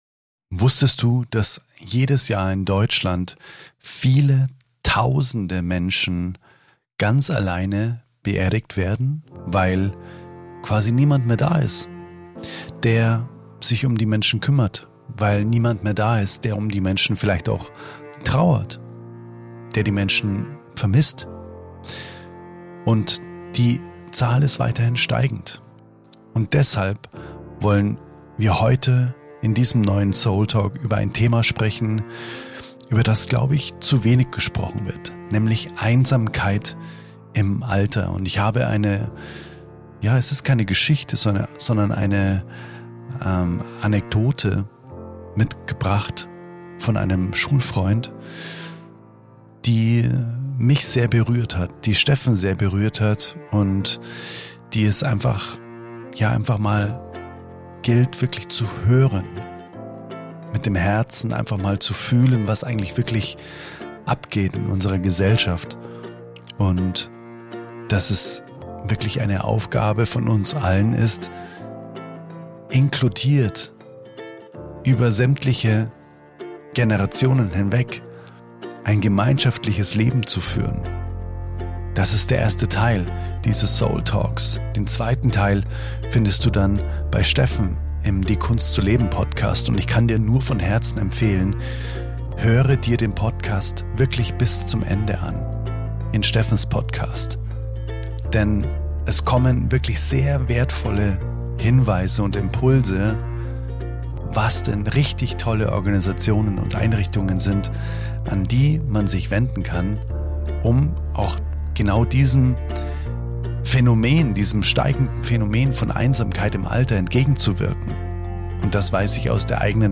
Einsam alt werden – und keiner sieht hin | Gespräch